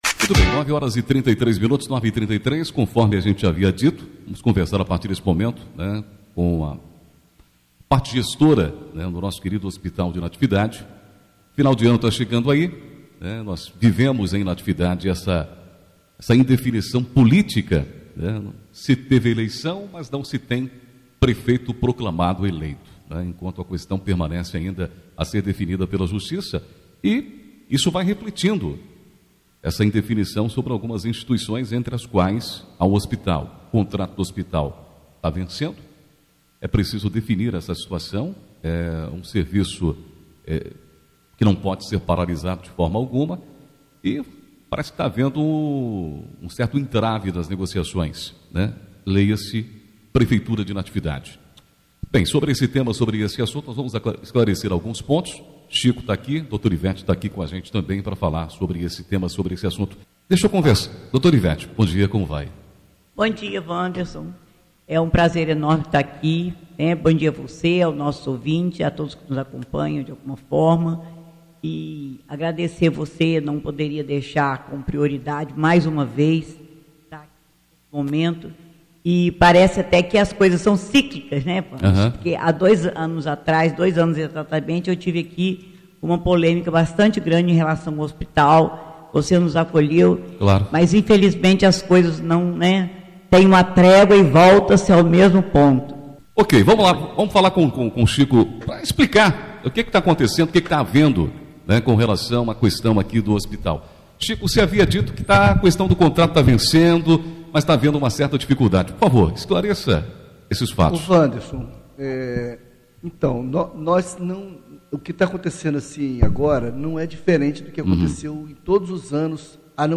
Na manhã desta segunda-feira (09), os gestores do Hospital Natividade concederam entrevista à Rádio Natividade, onde falaram sobre o impasse com a prefeitura para renovar o contrato de prestação de serviços com a instituição, que vencem em 31/12. A diretoria da unidade, sustentou que caso o situação não se resolva, poderá haver suspensão de serviços a população.
ENTREVISTA-HOSPITAL.mp3